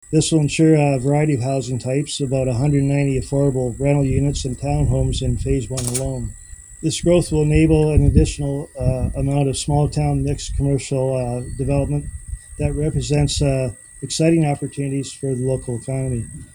In an announcement Wednesday morning in Shedden, the Township of Southwold is the recipient of almost $28 million in provincial funding.